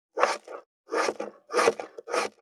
517切る,包丁,厨房,台所,野菜切る,咀嚼音,ナイフ,調理音,まな板の上,料理,
効果音